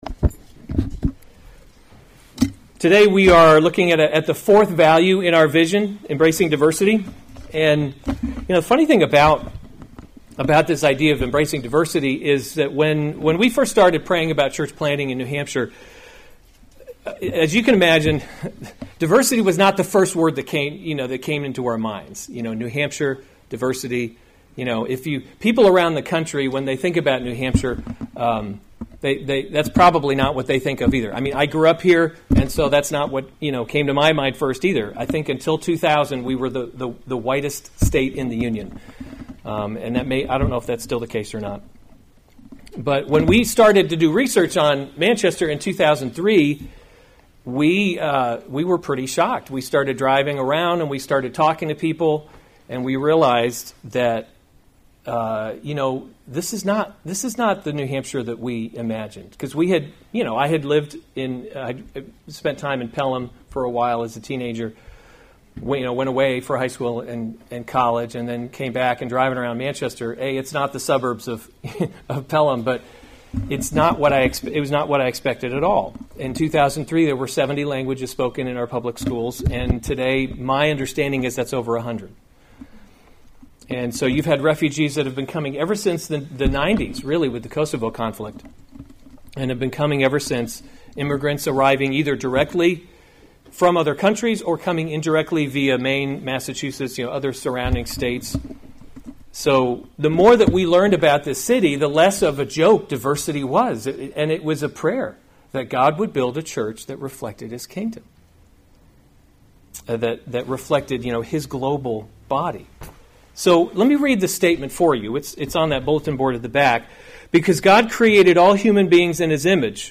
October 10, 2020 Reset series Weekly Sunday Service Save/Download this sermon Psalm 67 Other sermons from Psalm Make Your Face Shine upon Us To the choirmaster: with stringed instruments.